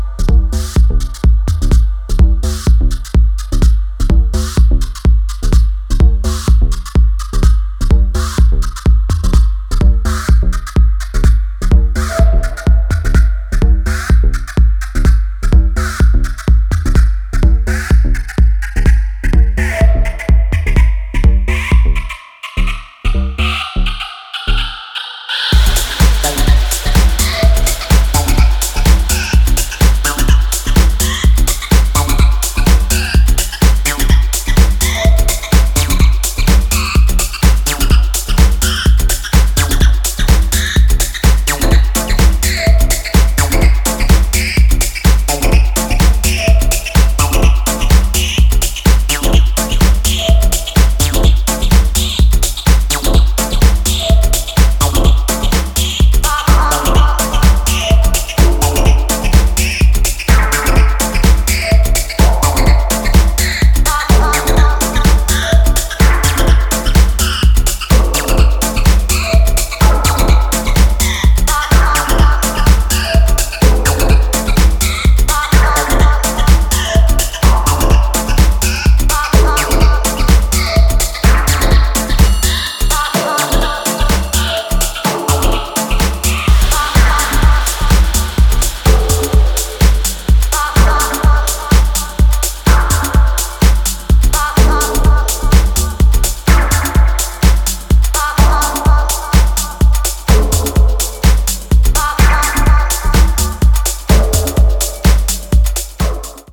軽やかな足回りと反響アシッドが深い時間に効能する